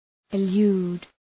Προφορά
{ı’lu:d}